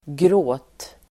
Uttal: [grå:t]